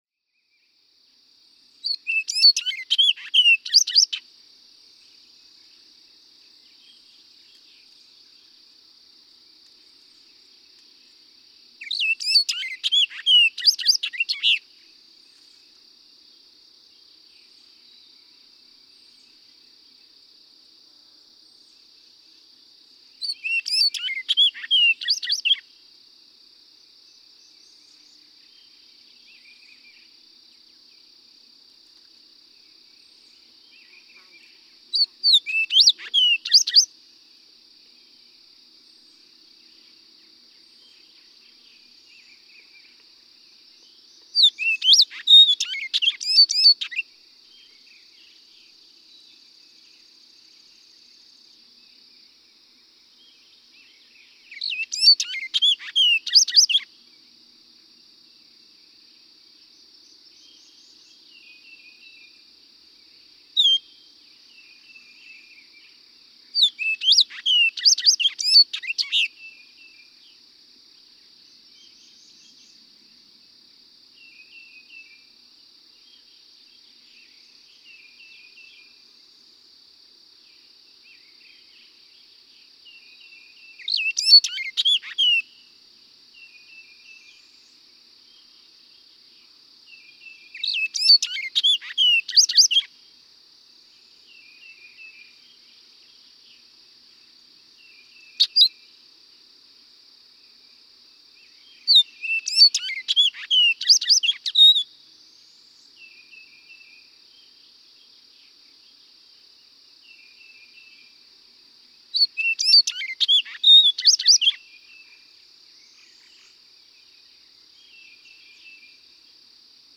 Orchard oriole
Song during the day, a rollicking jumble of buzzes and piping whistles.
Land Between the Lakes, Kentucky.
491_Orchard_Oriole.mp3